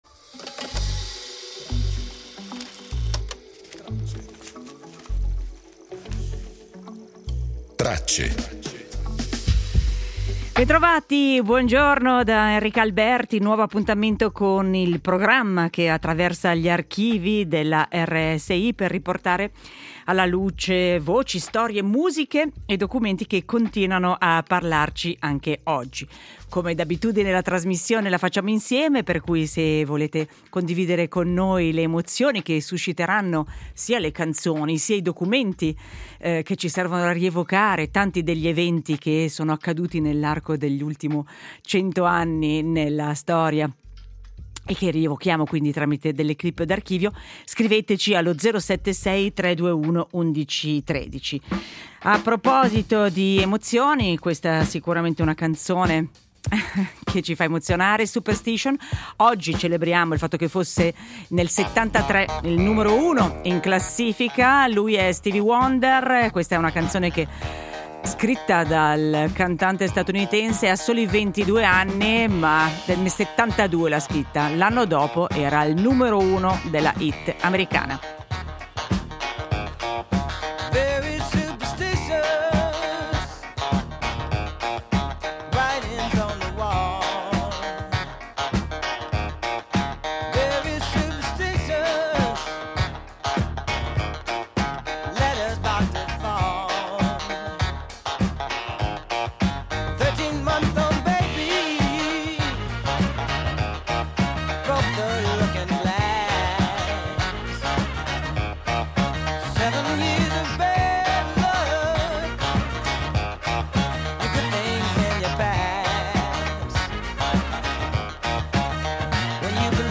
Nasceva oggi Wolfgang Amadeus Mozart, lo rievochiamo con uno sceneggiato del 1956, realizzato dalla nostra radio per festeggiare il bicentenario dalla nascita nel 1756.